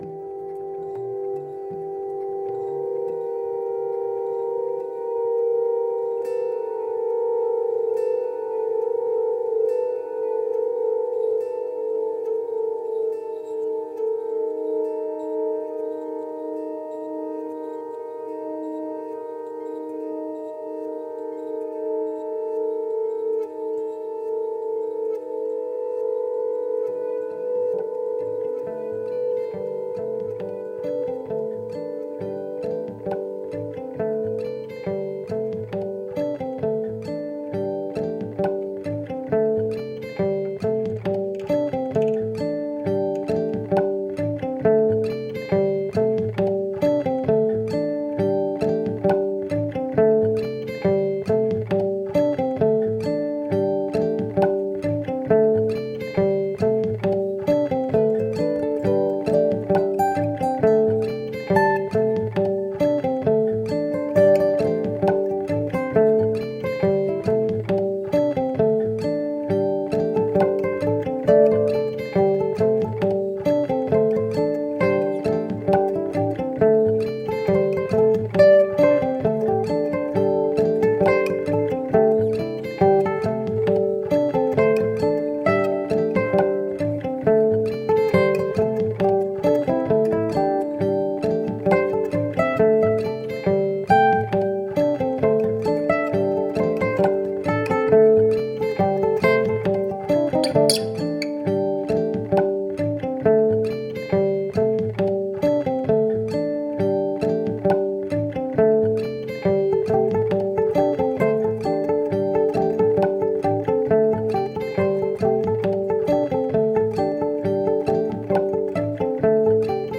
Looped acoustic guitar layers.